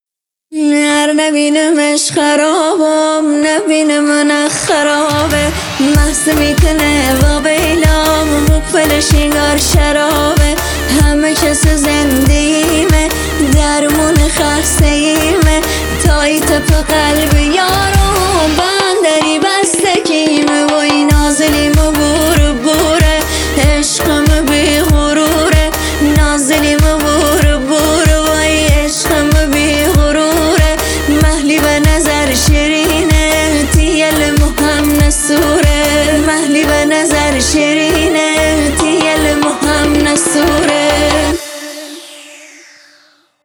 ریمیکس بیس دار اینستاگرام